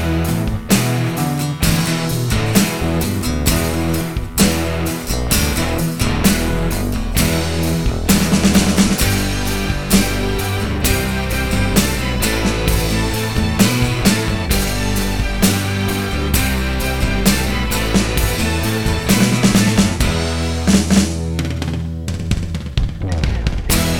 no Backing Vocals Glam Rock 2:26 Buy £1.50